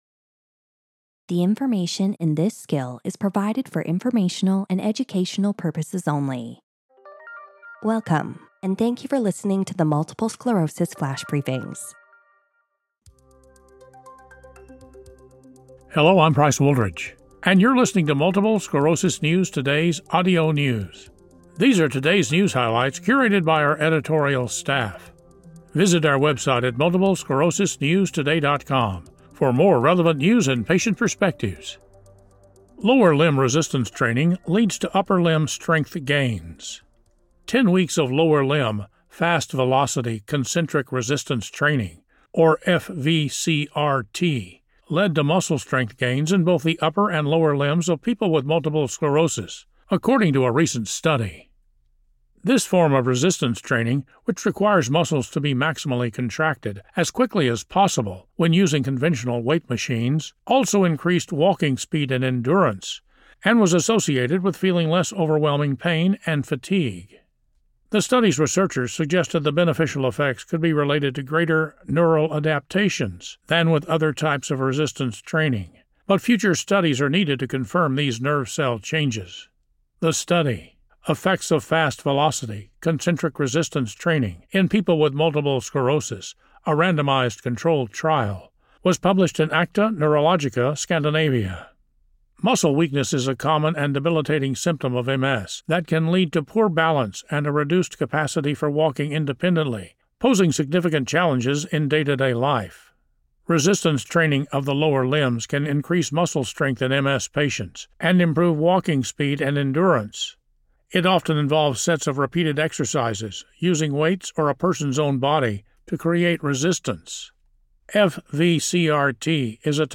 reads a news article